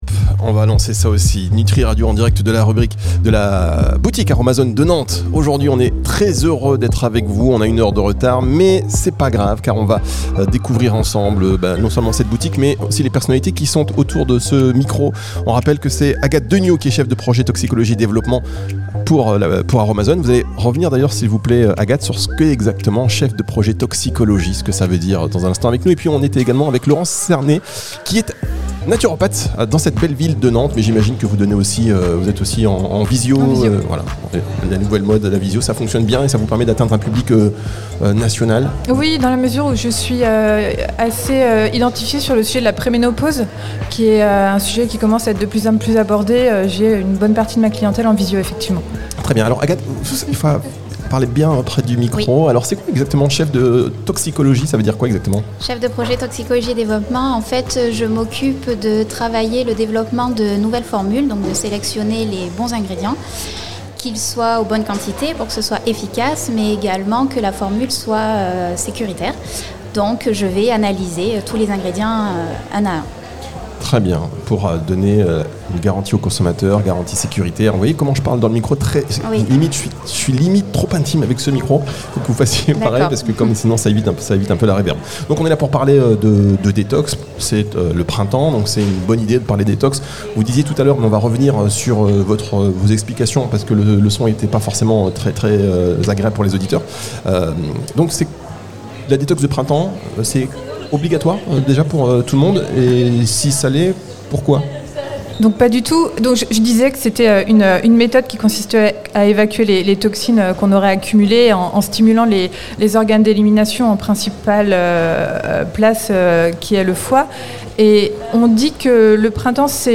En direct de la boutique Aroma Zone de Nantes.